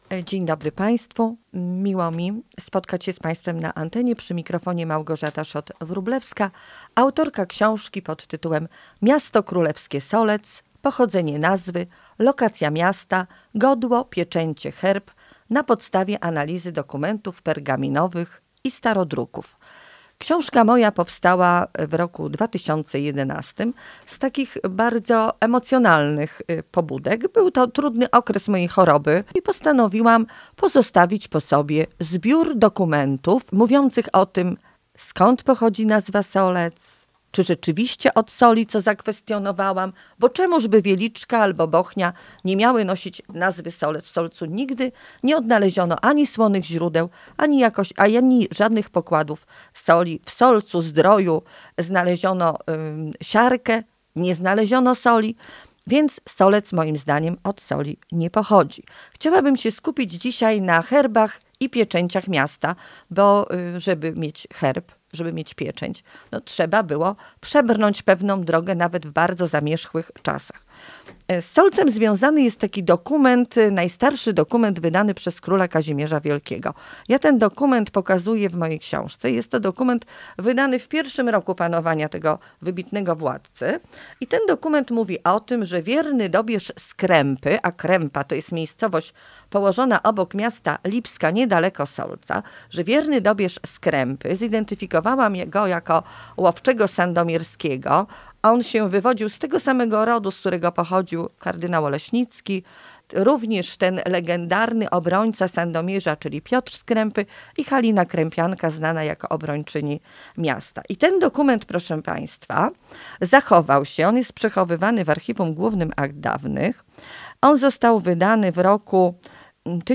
Rozmowa z Gościem Radia Leliwa